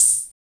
OpenHH Groovin 4.wav